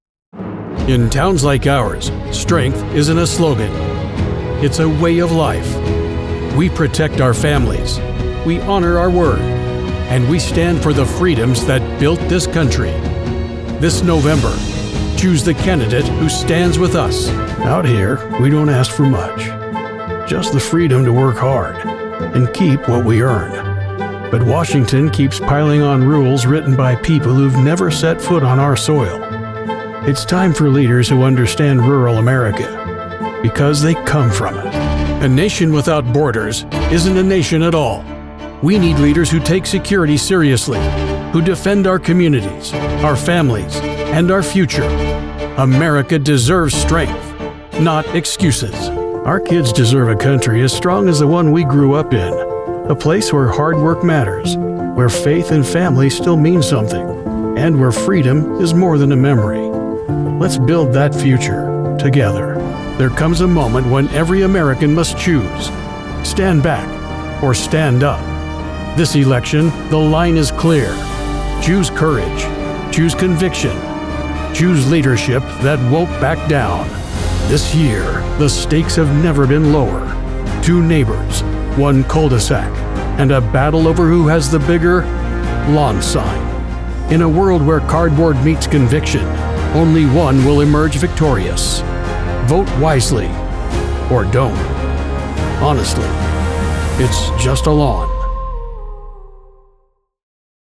• A gritty, resonant delivery shaped by Idaho mountain life
• Cinematic presence ideal for high‑impact political ads
• Clear, steady narration for long‑form political content
All recordings are delivered from a professional studio with clean, broadcast‑ready audio.
• Flexible performance range from calm authority to intense urgency
Political Voice Over Demo
The sound is polished, consistent, and engineered for high‑impact messaging.